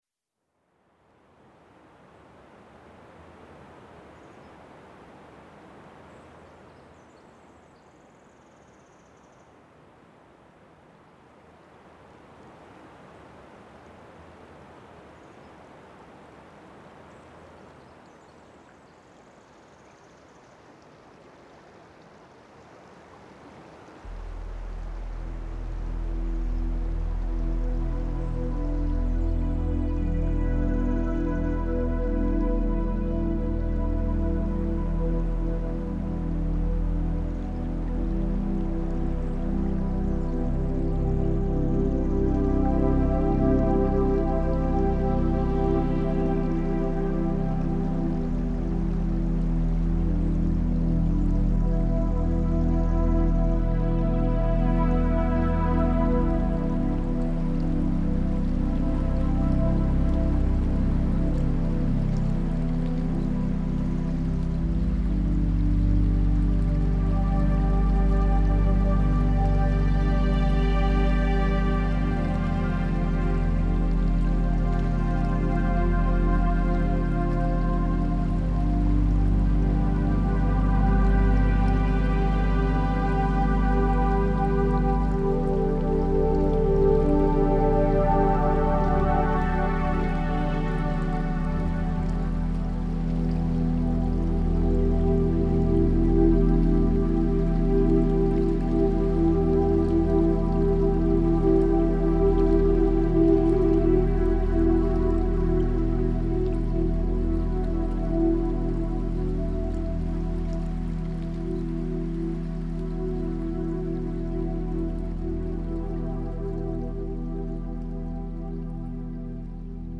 Music composed by Michael Koch, who also composed Aqua Aura, Aria and Secret Island